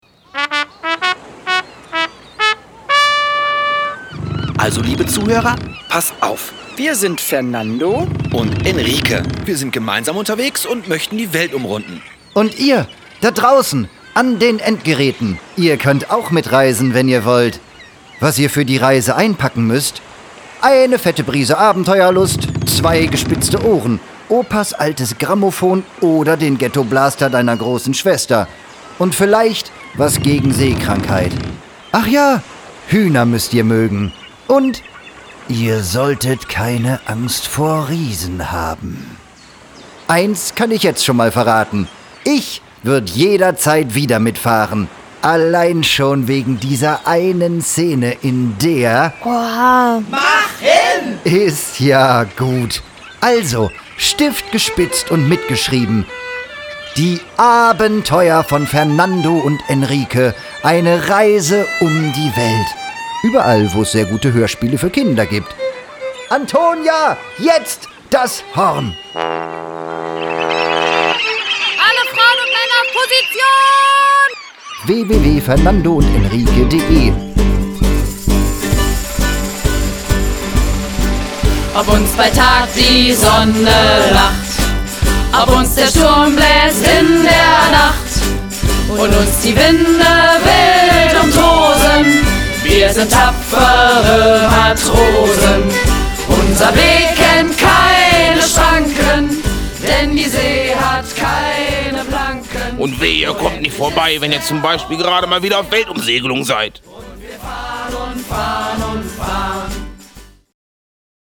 Ein Hörspiel in zwei Teilen, mit viel Musik und frechen Sprüchen.